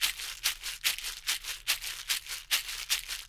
BOL SHAKER.wav